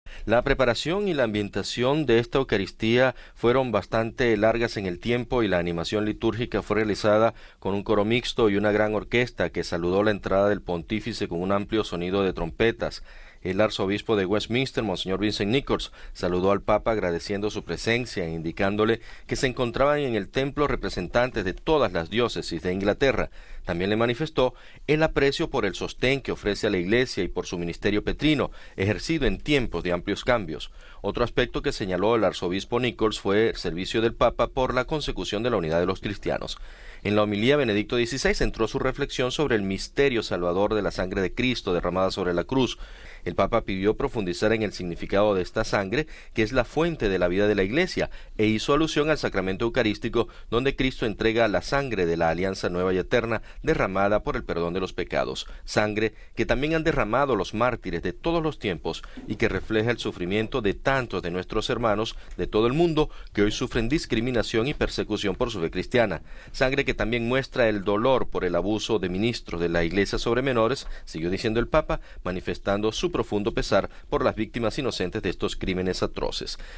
Crónica de la celebración RealAudio